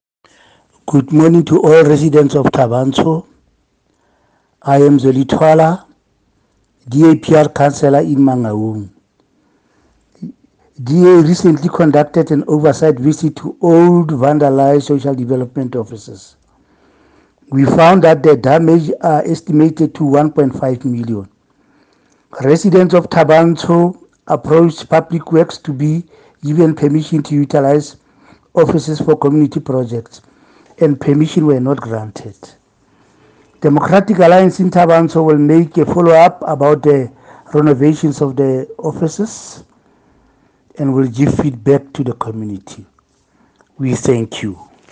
Sesotho soundbites by Cllr Zweli Thwala.